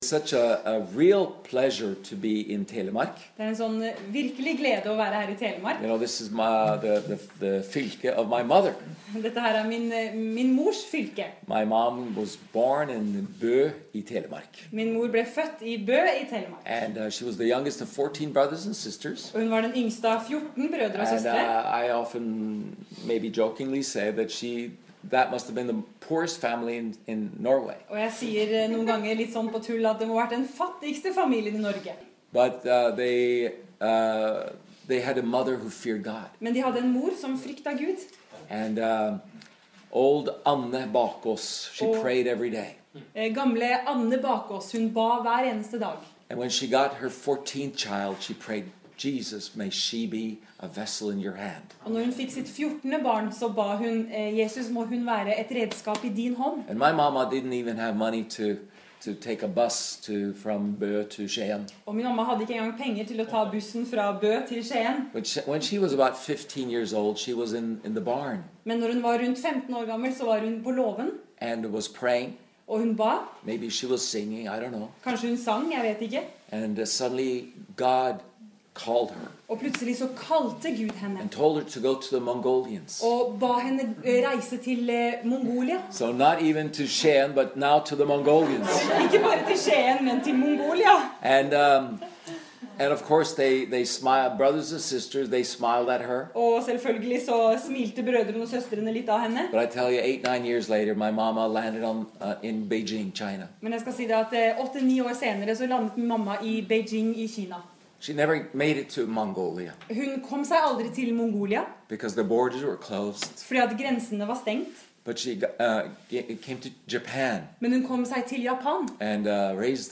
spoke in «House on Fire», Stathelle, Telemark, Norway on November 7th